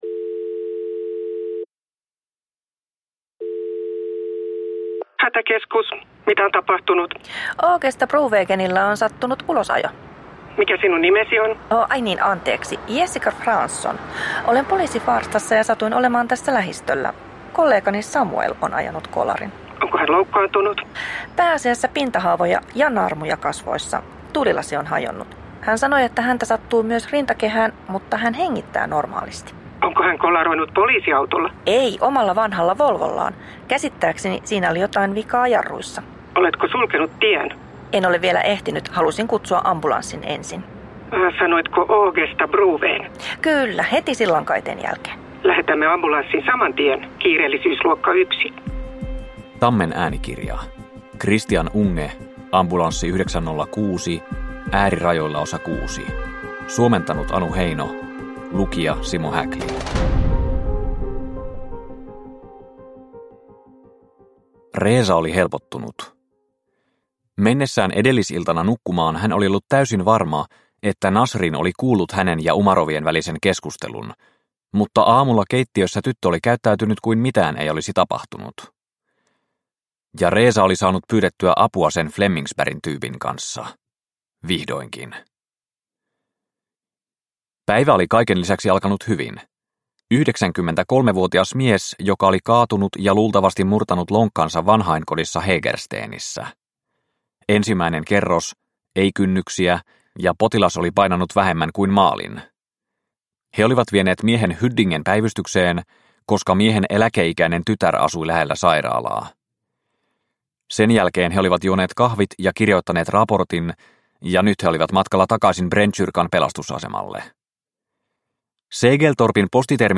Ambulanssi 906 Osa 6 – Ljudbok – Laddas ner